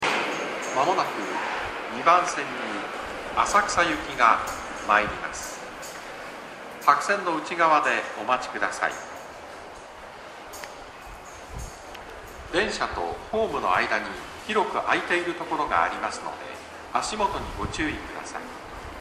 スピーカーは天井型で音質は比較的いいです。銀座線ホームは天井もやや低めなので収録がしやすいです。
接近放送各駅停車　浅草行き接近放送です。